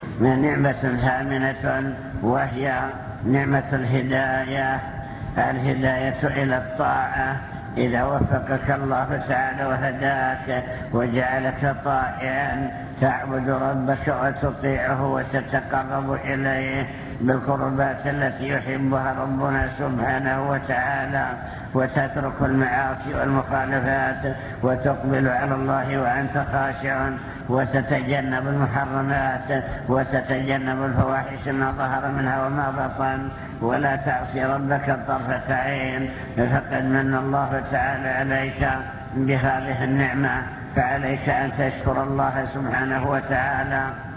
المكتبة الصوتية  تسجيلات - محاضرات ودروس  محاضرة بعنوان شكر النعم (2) نعم الله تعالى وعظمها